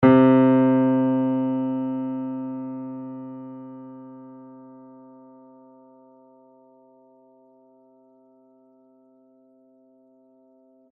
piano
C3.mp3